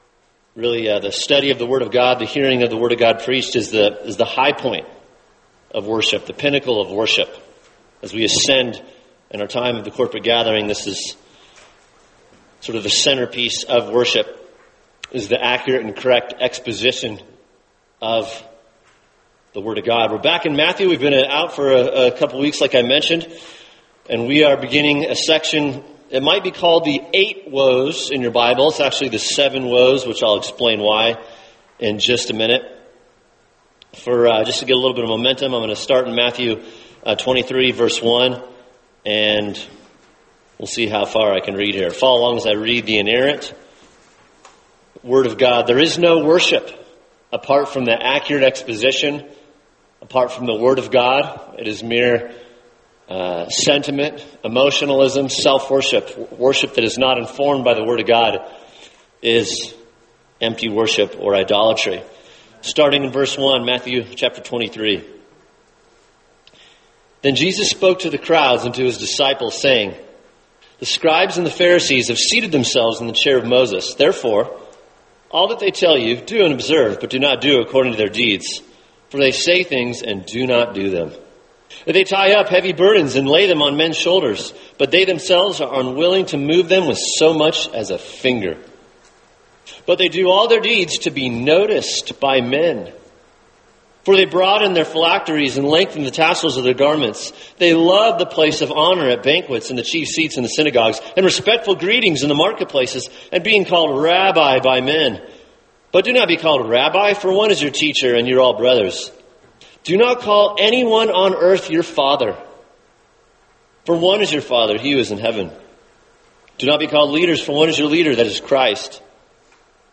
[sermon] Matthew 23:13-22 The Kind Of Leader God Condemns – Part 1 | Cornerstone Church - Jackson Hole